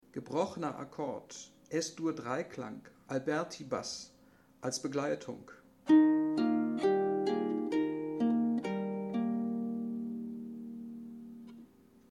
Gebrochener Akkord als Alberti-Baß